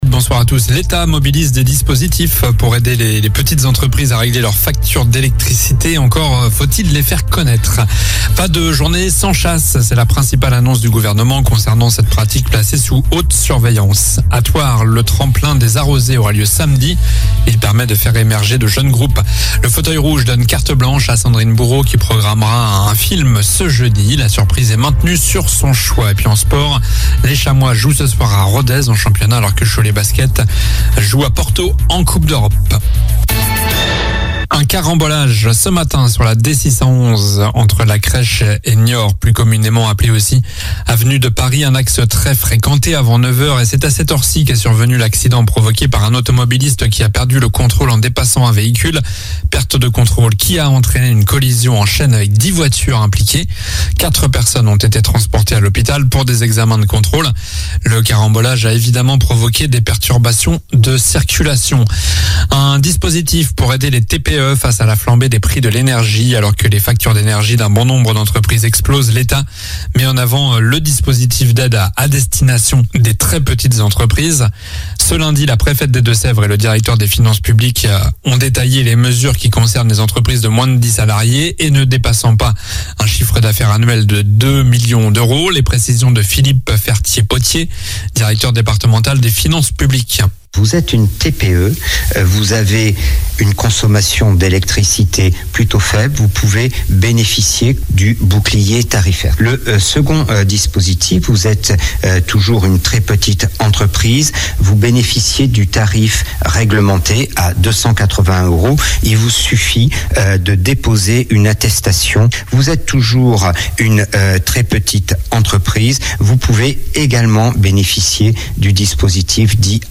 Journal du mardi 10 janvier (soir)